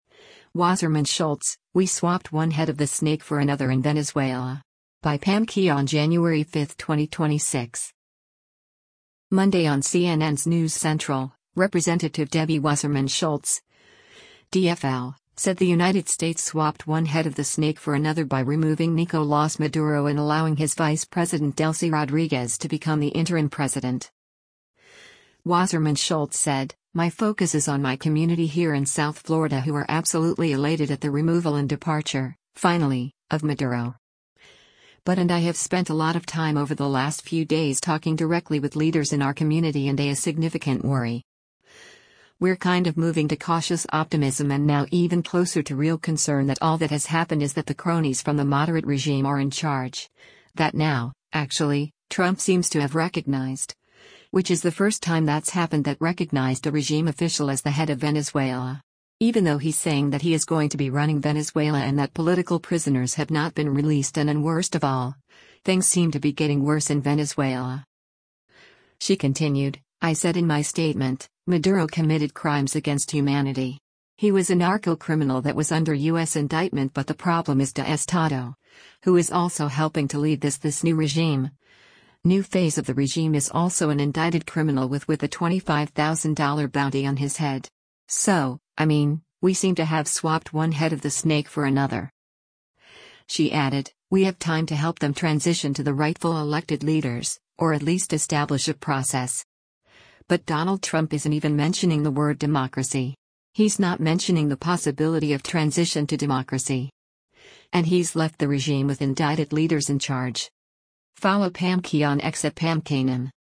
Monday on CNN’s “News Central,” Rep. Debbie Wasserman Schultz (D-FL) said the United States “swapped one head of the snake for another” by removing Nicolás Maduro and allowing his vice president Delcy Rodriguez to become the interim president.